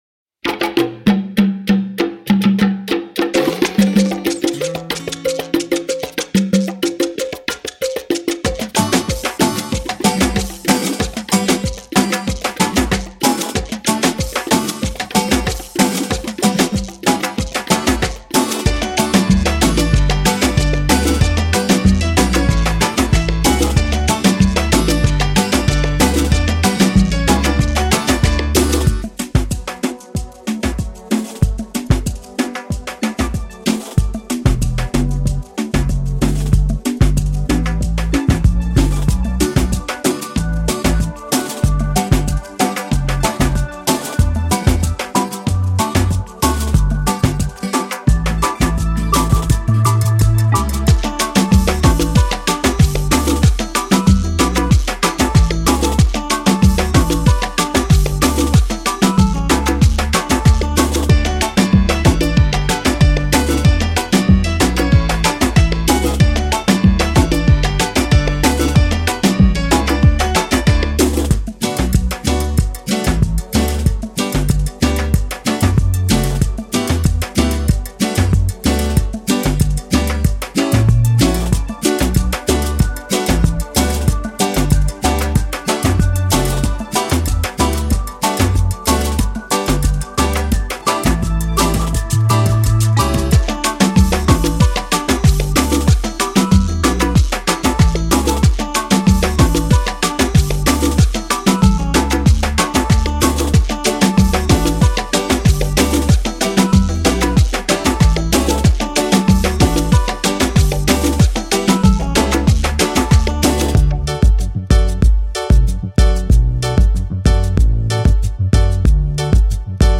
Instrumentale versie: